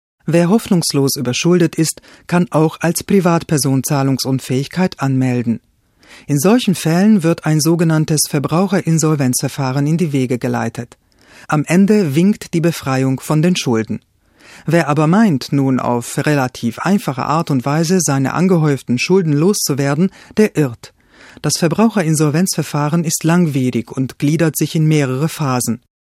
Kein Dialekt
Sprechprobe: Sonstiges (Muttersprache):
hungarian female voice over artist